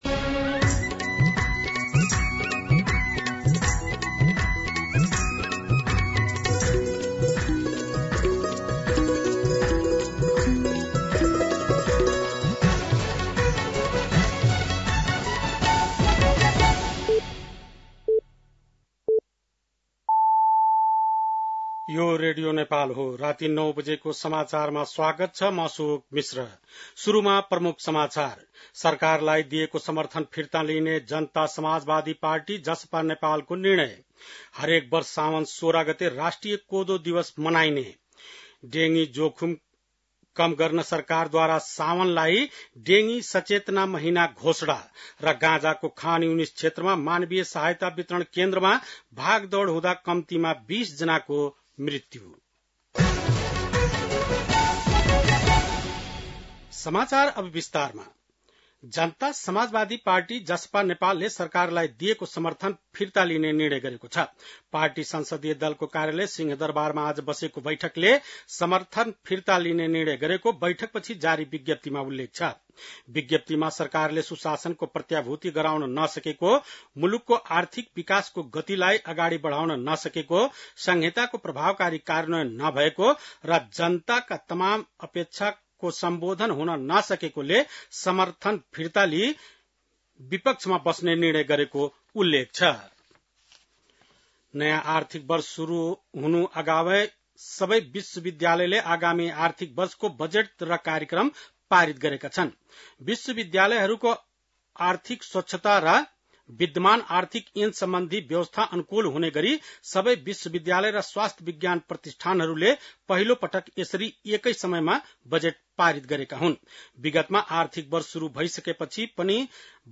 बेलुकी ९ बजेको नेपाली समाचार : ३२ असार , २०८२